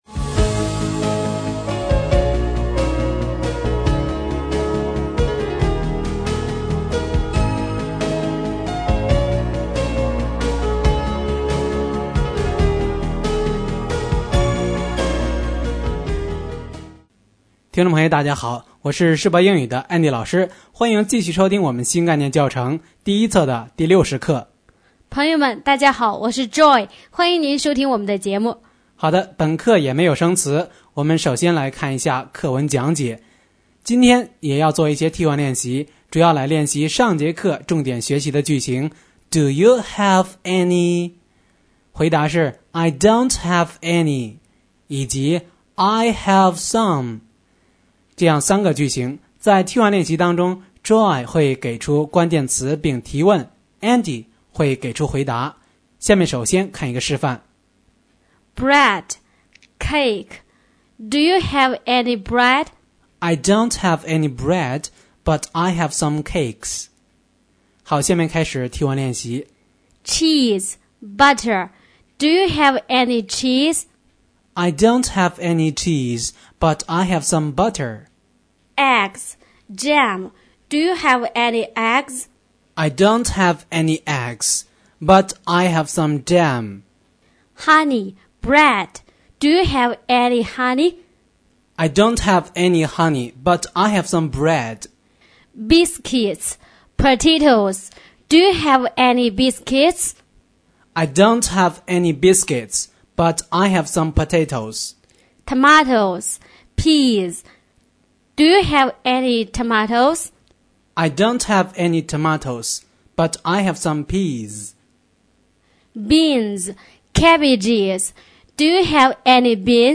新概念英语第一册第60课【课文讲解】